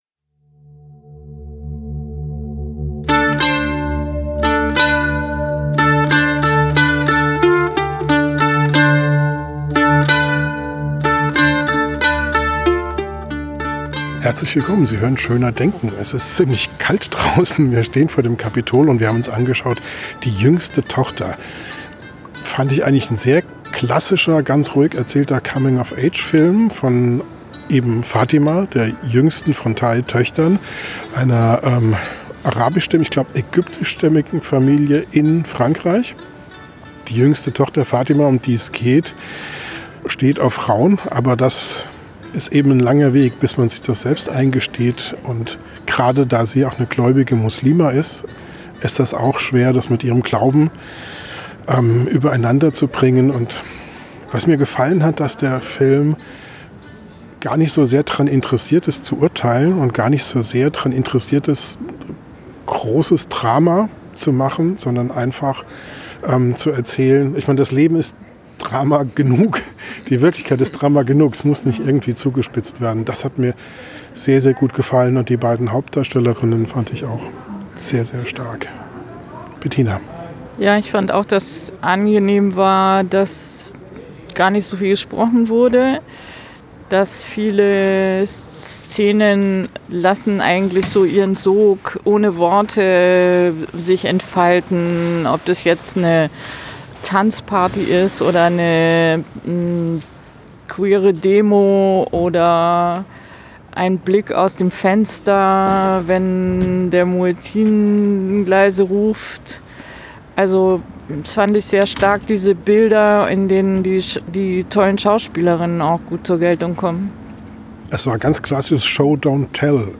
Der erste Eindruck direkt nach dem Kino